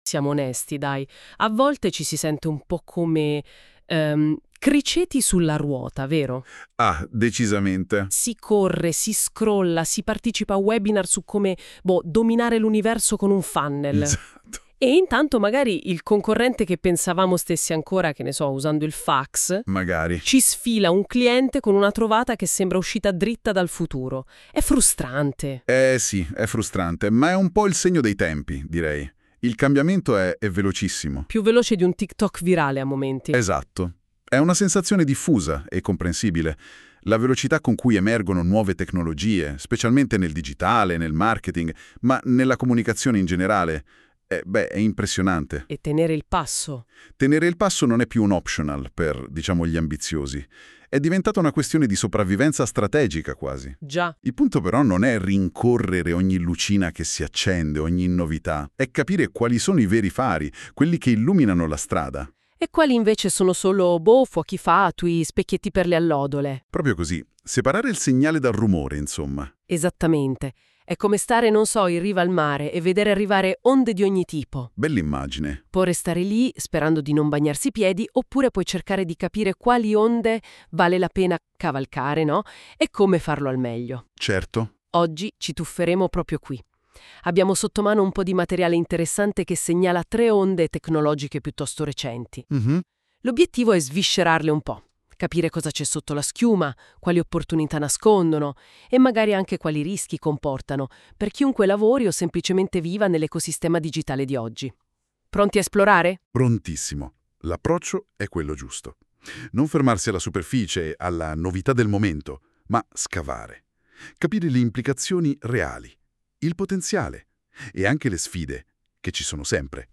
Prova ad ascoltare la versione di questo articolo audio-intervista a due voci!